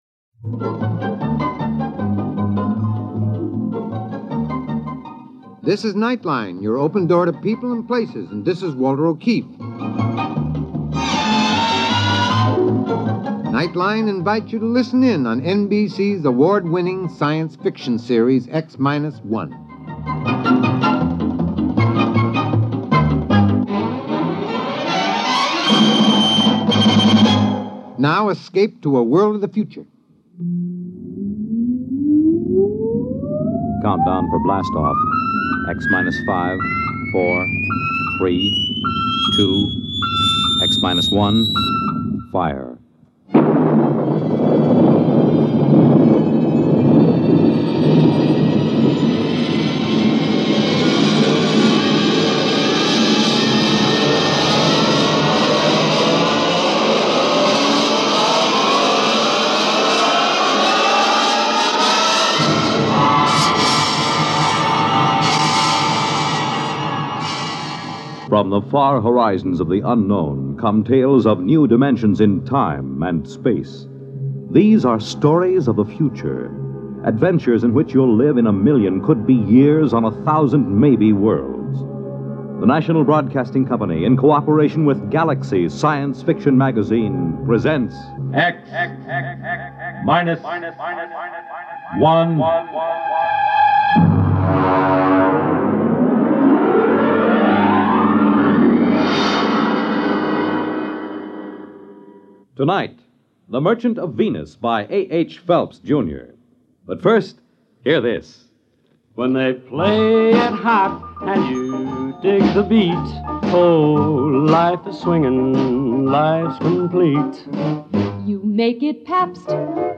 Twelve episodes of classic science fiction by some of the genre’s top authors are ready to blast off in X Minus One, Volume 9, restored to sparkling audio quality by Radio Archives!